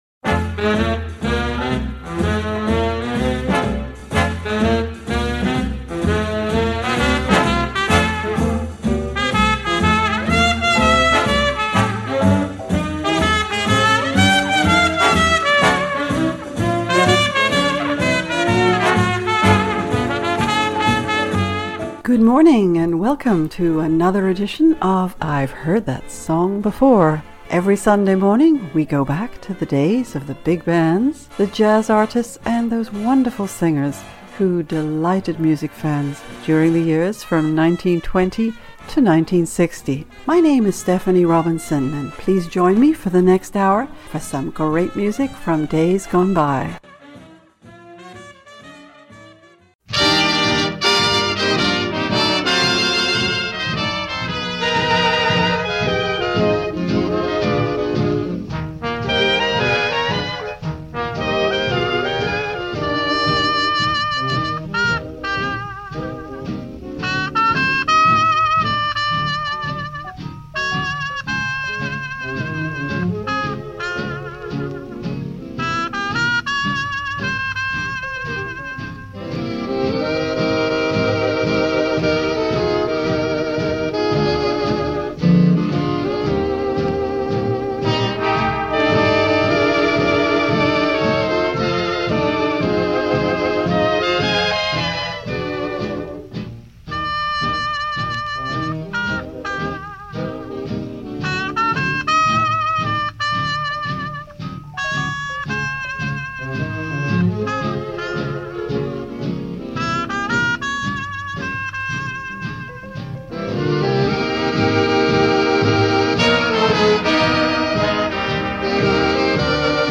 big bands
And we listen too to some excellent small jazz groups